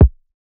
TS Kick_5.wav